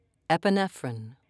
(ep-i-ne'frin)